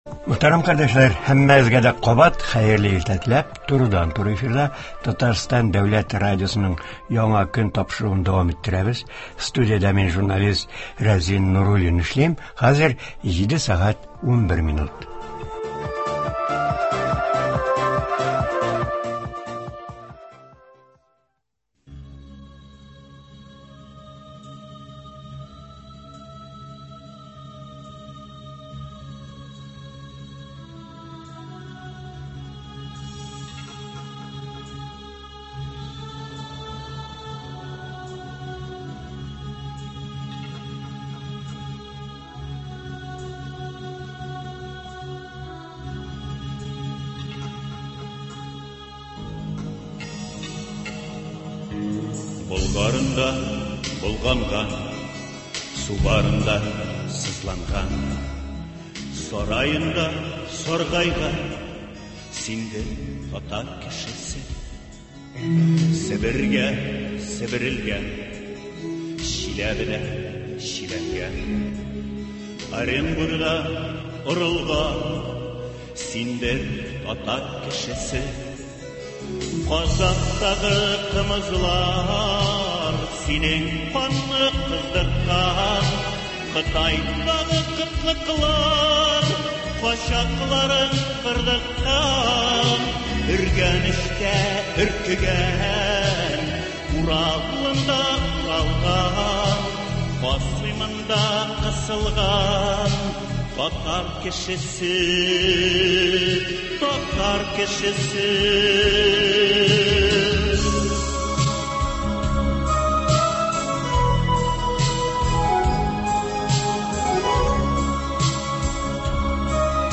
Туры эфир (30.06.21)
тыңлаучыларны кызыксындырган сорауларга җавап бирә